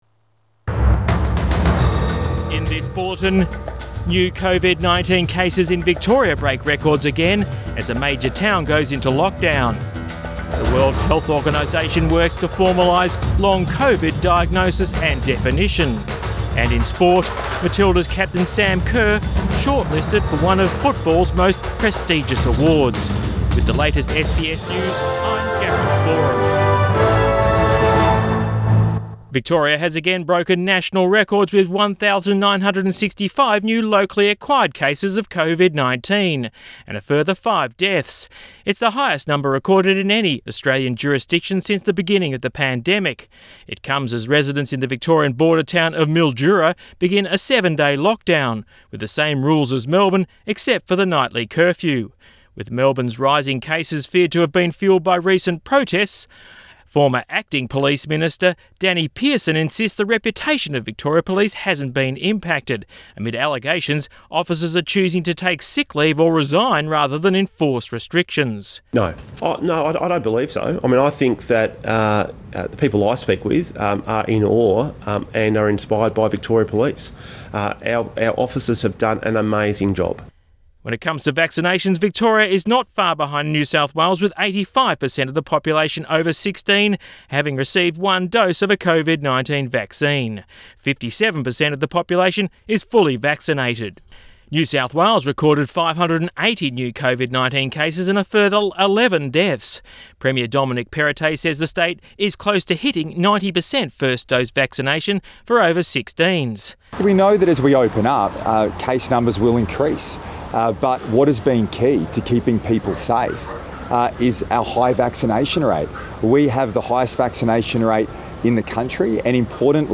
Midday bulletin 9 October 2021